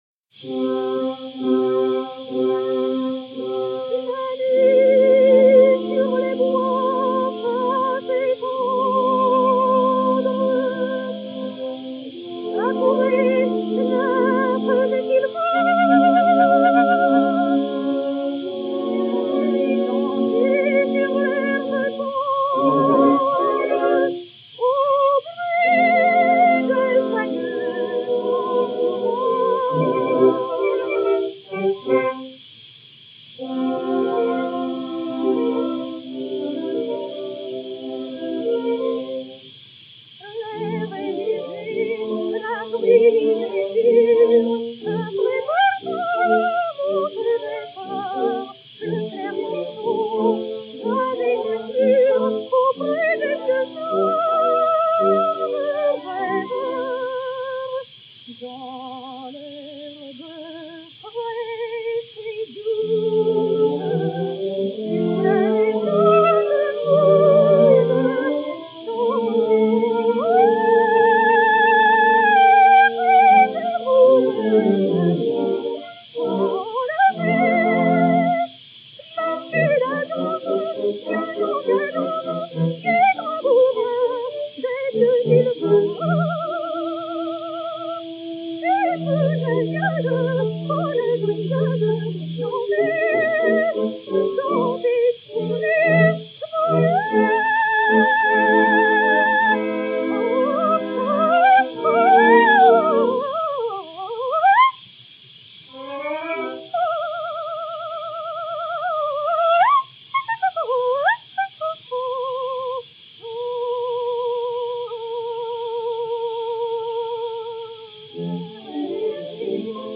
soprano de l'Opéra-Comique Orchestre
mélodie (Armand Ocampo / Herman Bemberg)
Orchestre
Odéon X 97764-2, mat. xP 5232-2, enr. à Paris en 1911